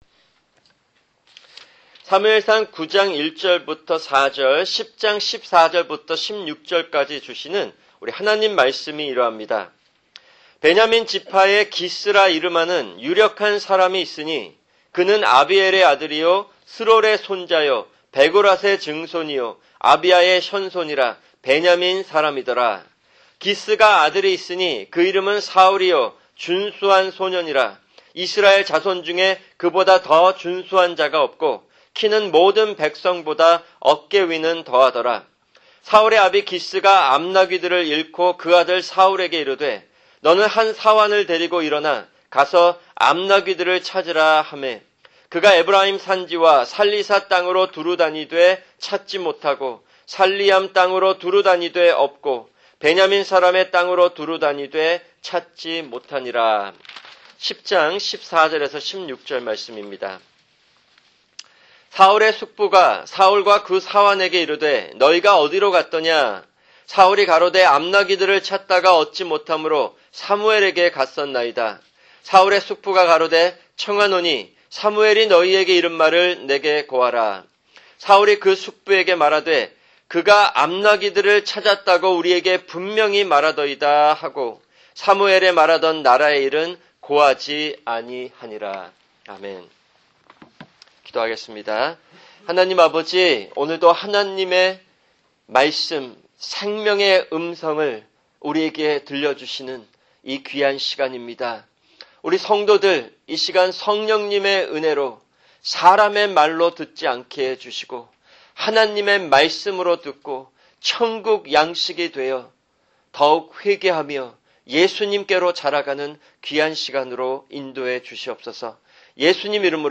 [주일 설교] 사무엘상(25) 9:1-10:16(1)